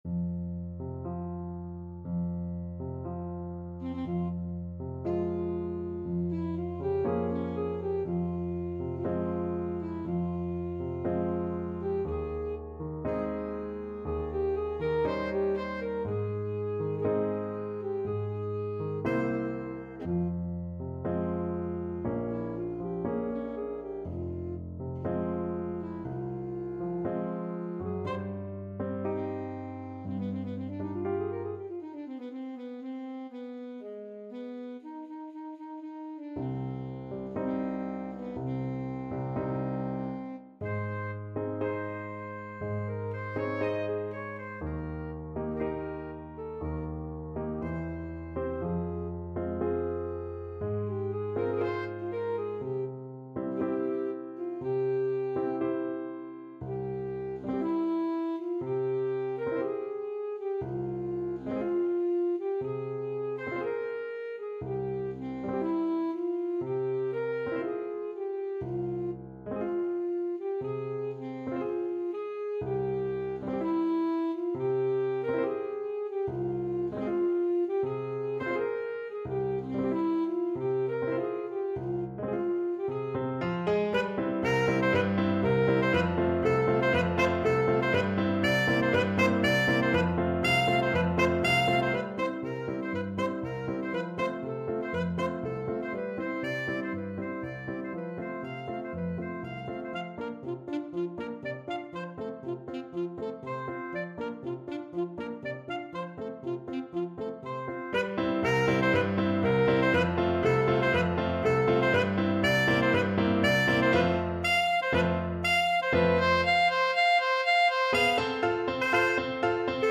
Classical Liszt, Franz Hungarian Rhapsody No. 2 Alto Saxophone version
Alto Saxophone
2/4 (View more 2/4 Music)
F minor (Sounding Pitch) D minor (Alto Saxophone in Eb) (View more F minor Music for Saxophone )
Andante mesto = 60
Classical (View more Classical Saxophone Music)